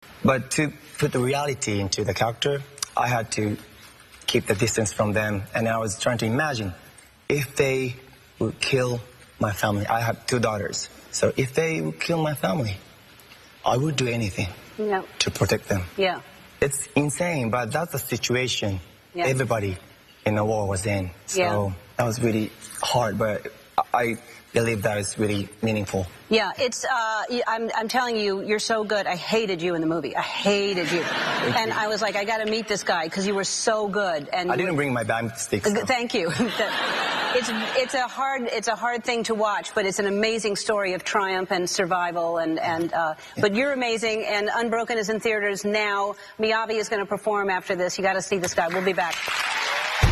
在线英语听力室艾伦脱口秀第105期:精彩故事的听力文件下载,艾伦脱口秀是美国CBS电视台的一档热门脱口秀，而主持人Ellen DeGeneres以其轻松诙谐的主持风格备受青睐。